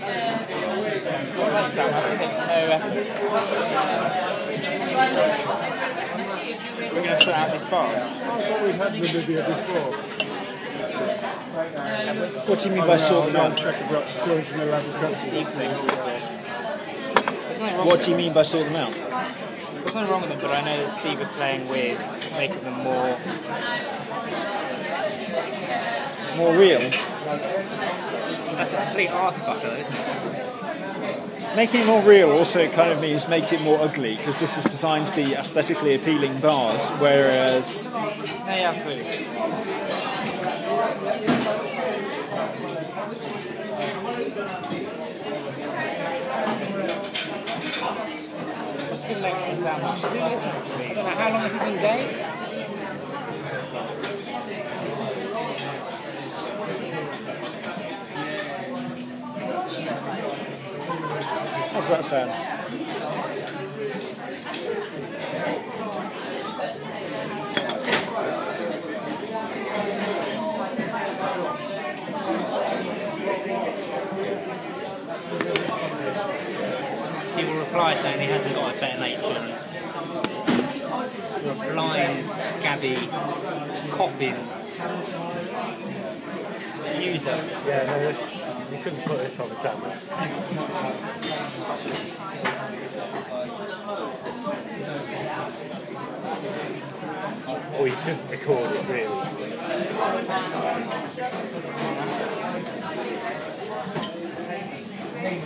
Restaurant at lunch time